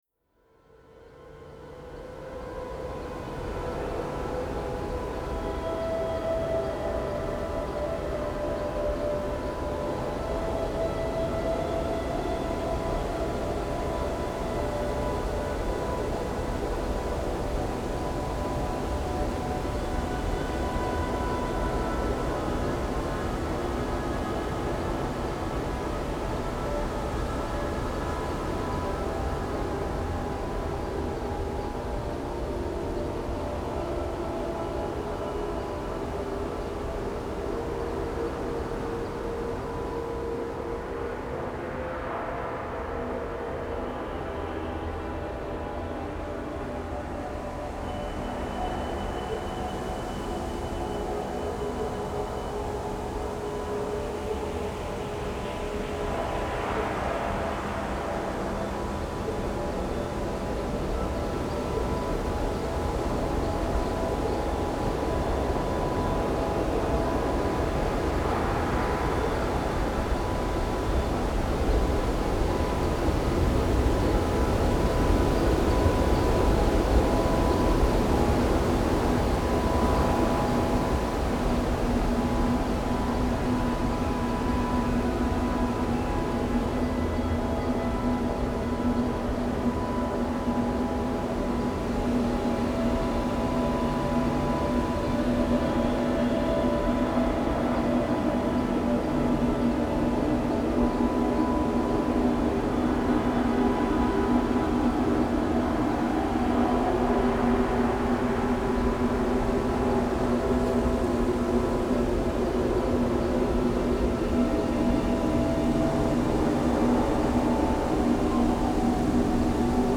Wind_Ambiance1
WindAmbiance.mp3